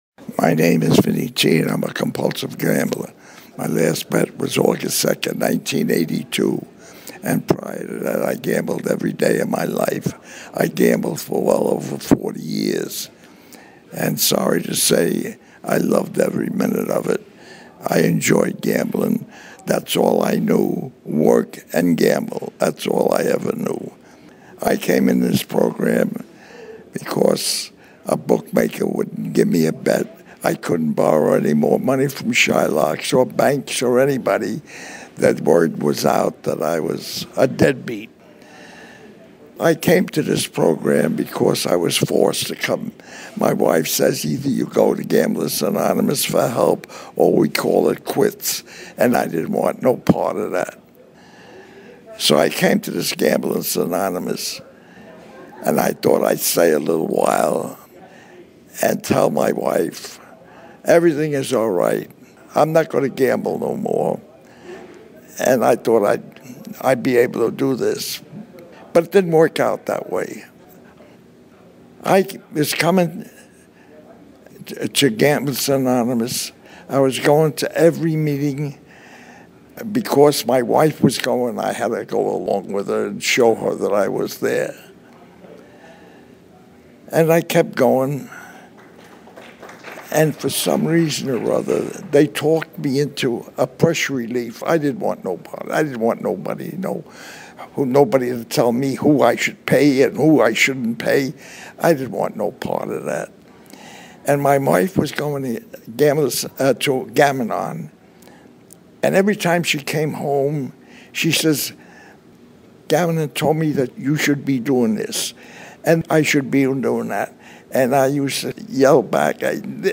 GA Speaker Audios